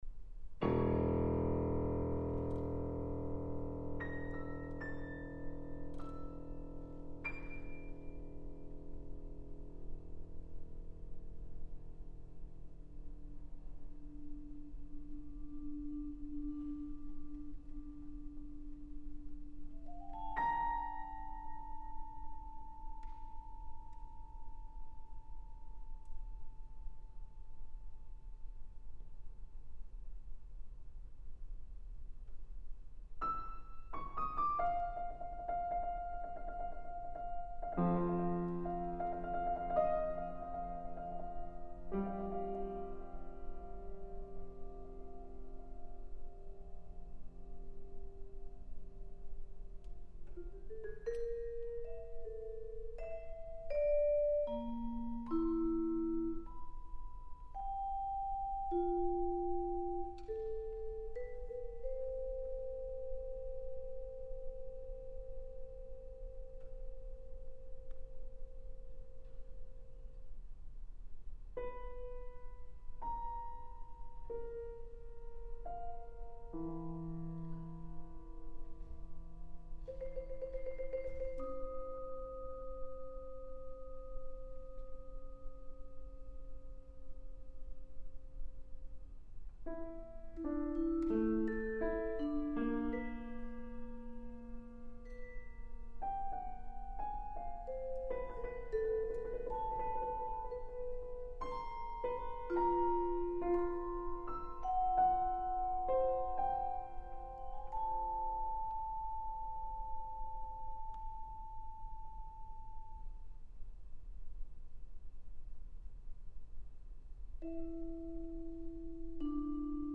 Composition for Vibraphone and Piano for vibraphone and piano.
A composition investigating temporal fixity in a fluid sonic environment.